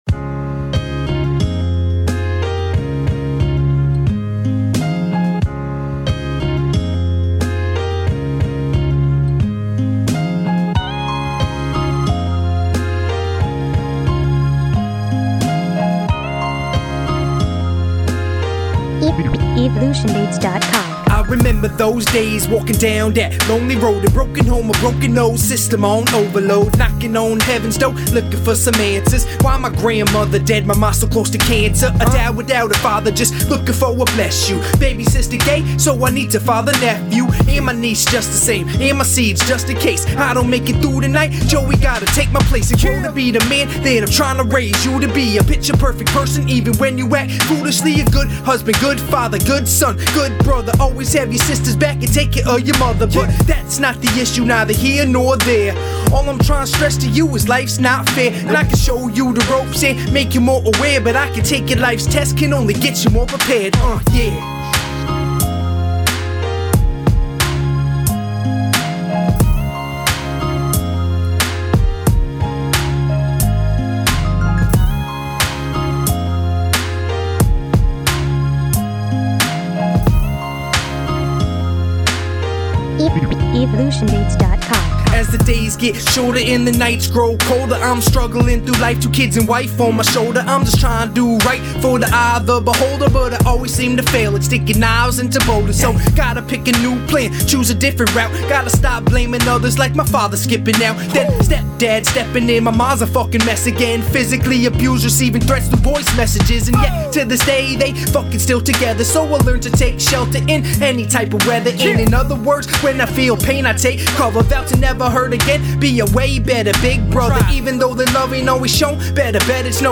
just wanna get it a little fuller any sugestions?? would be very appriciated thx...i didtn put in choras yet just want some info on 1st 2 verses